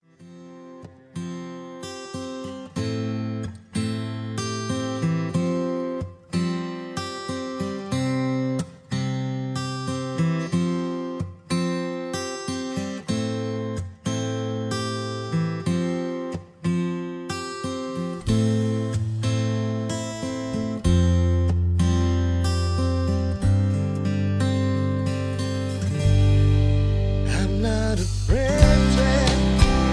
Key-C) Karaoke MP3 Backing Tracks